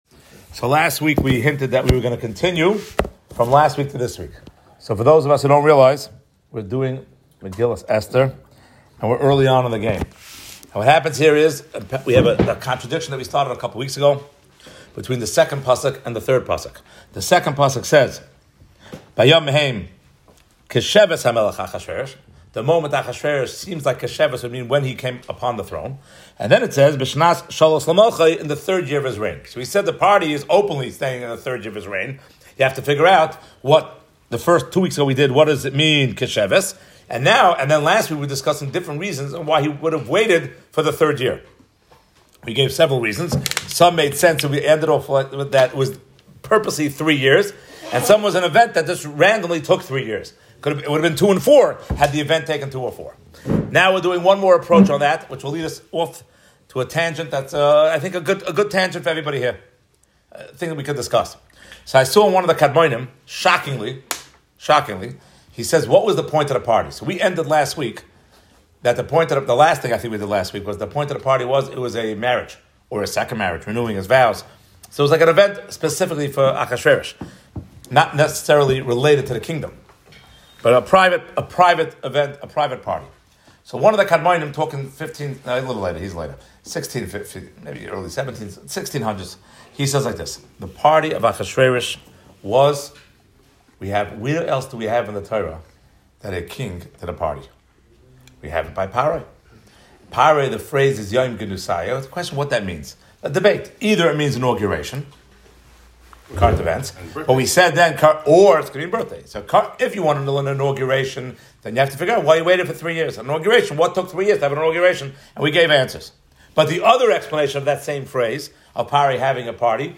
From Young Israel Beth-El, in Borough Park, Brooklyn